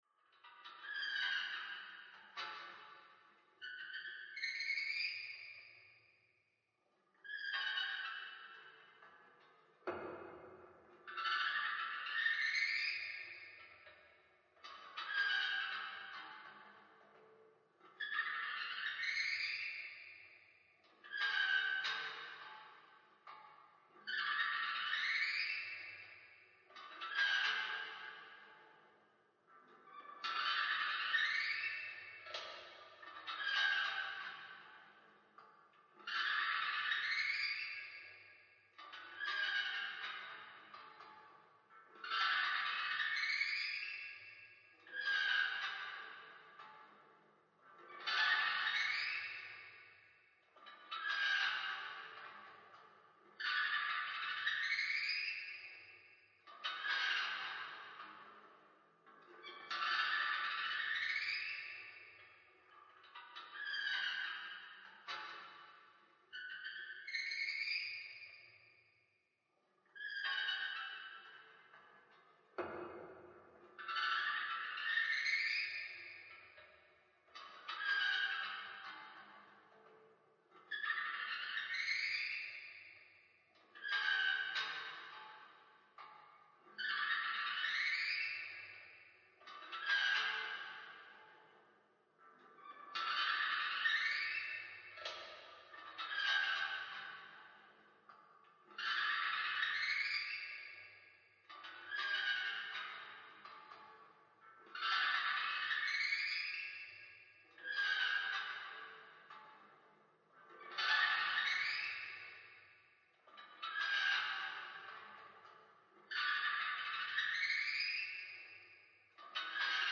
Здесь вы найдете жуткие стоны, таинственные шорохи, зловещий смех и другие эффекты, которые сделают ваш праздник по-настоящему пугающим.
Когда в подвале раздаются жуткие скрипы, лучше сходить и проверить